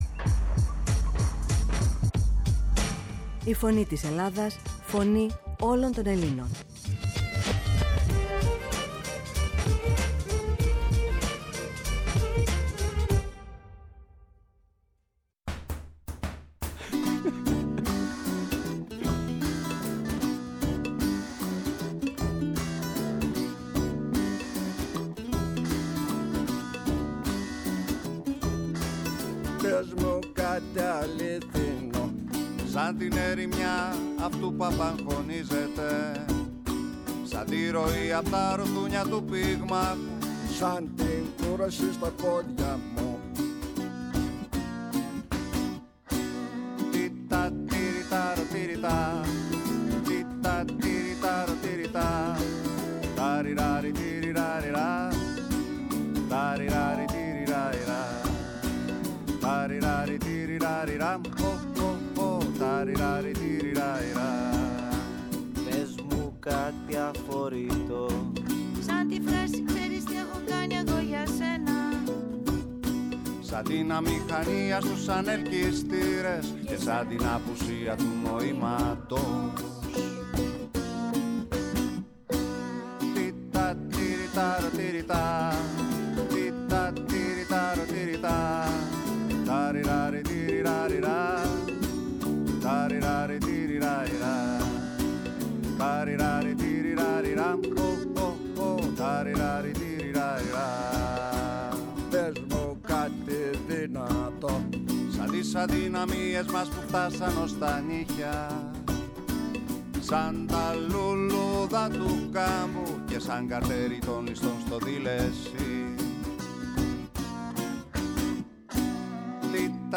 Παράλληλα, στην εκπομπή μεταδόθηκαν τραγούδια της στα ελληνικά και στα γερμανικά.